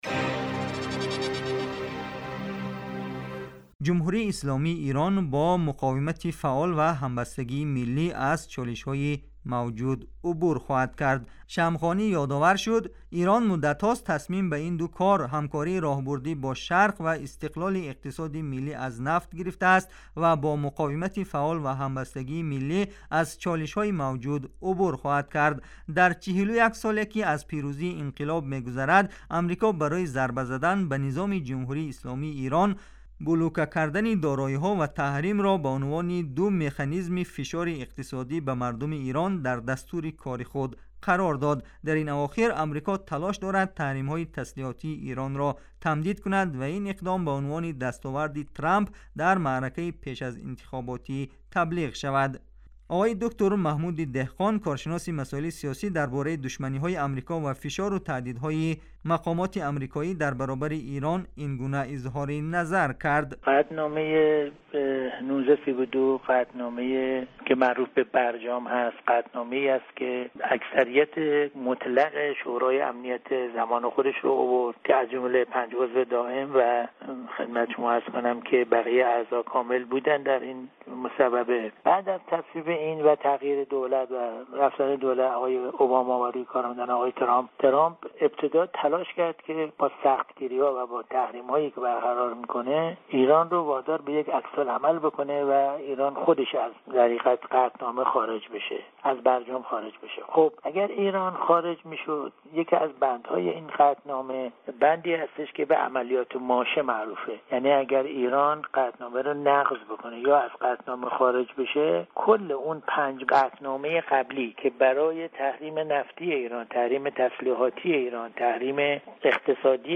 Гузориши вижа: Нигоҳ ба шарқ; посухи Эрон ба фишорҳои Амрико